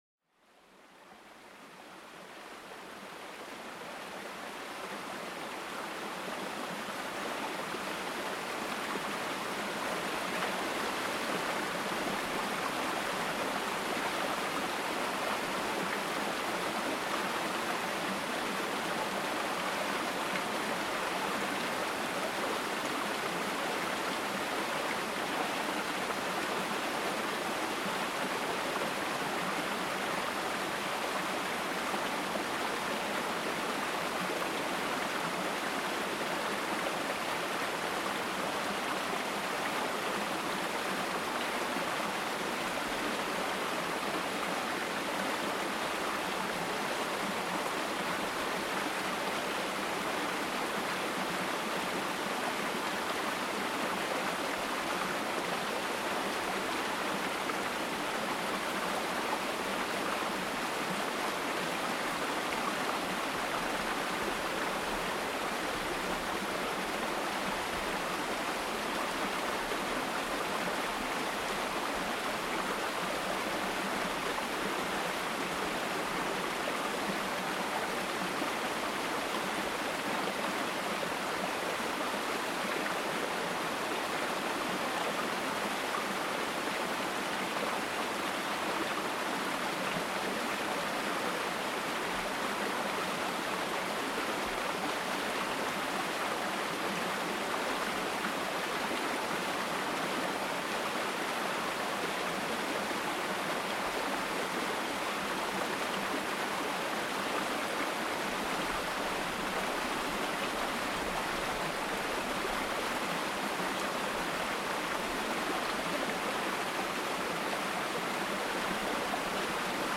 Le flux apaisant d'une grande rivière pour calmer l'esprit